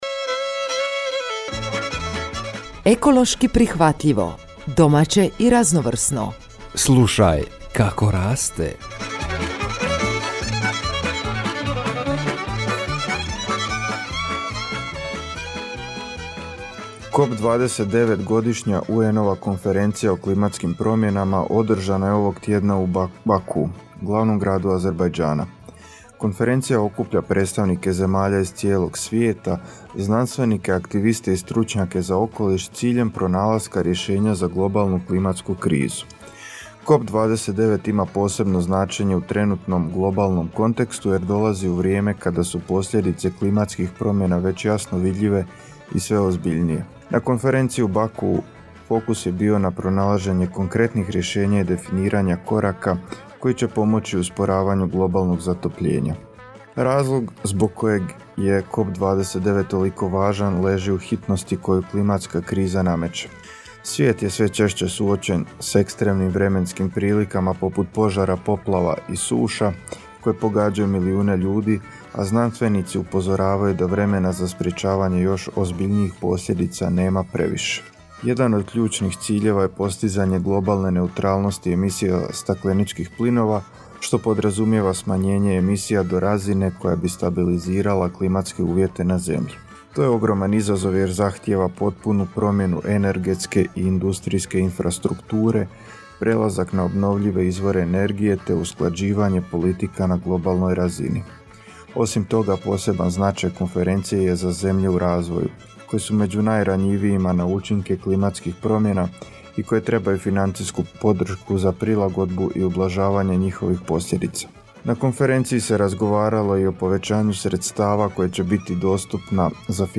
Informativna emisija o UN-ovoj konferenciji o klimi COP29 i postignutom dogovoru oko međunarodnog klimatskog financiranja. Ovaj edukativni sadržaj objavljujemo u obliku radijske emisije koju možete uvijek ponovno poslušati.